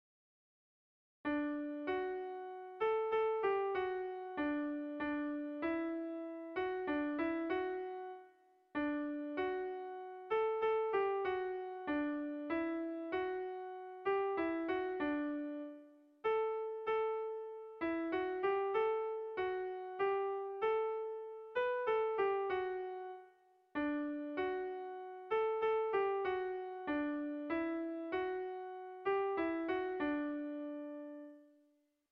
Air de bertsos - Voir fiche   Pour savoir plus sur cette section
Erromantzea
Zortziko txikia (hg) / Lau puntuko txikia (ip)
A1A2BA2